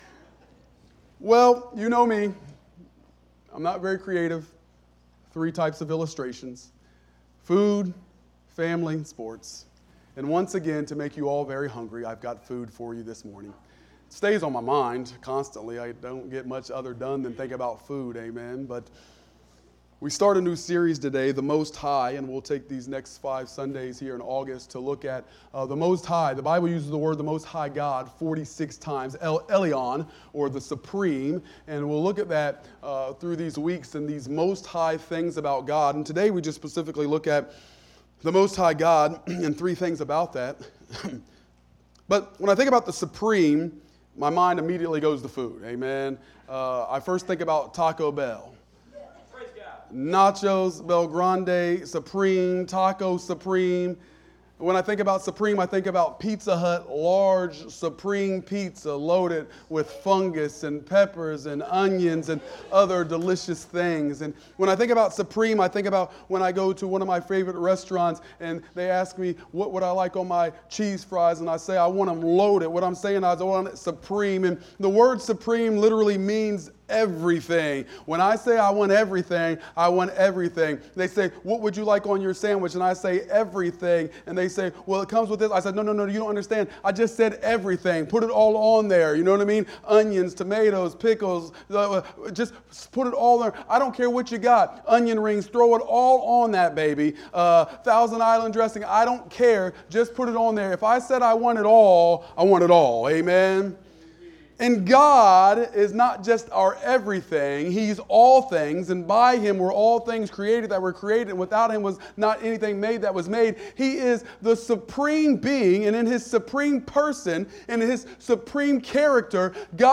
Morning Worship Service